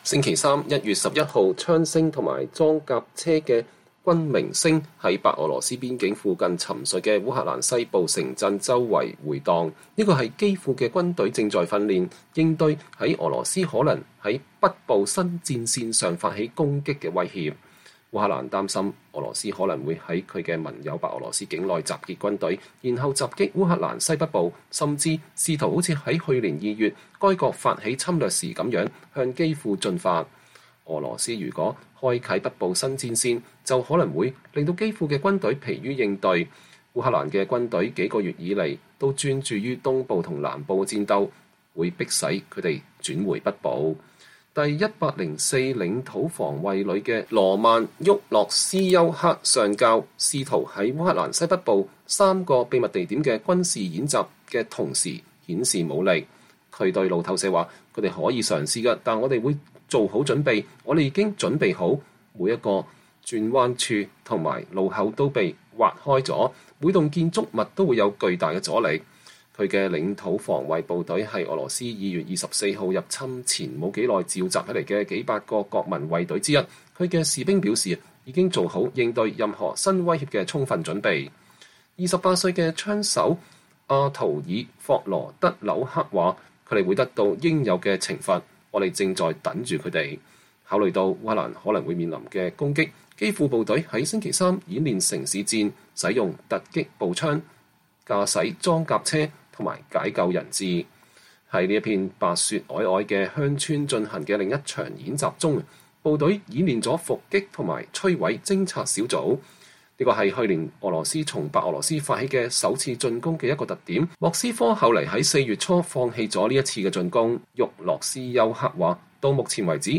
週三(1月11日)，槍聲和裝甲車的轟鳴聲在白俄羅斯邊境附近沈睡的烏克蘭西部城鎮迴蕩，這是基輔的軍隊正在訓練，以應對俄羅斯可能在北部新戰線上發起攻擊的威脅。